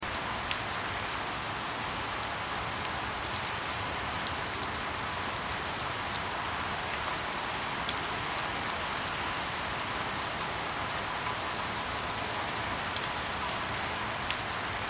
دانلود آهنگ باران 26 از افکت صوتی طبیعت و محیط
جلوه های صوتی
دانلود صدای باران 26 از ساعد نیوز با لینک مستقیم و کیفیت بالا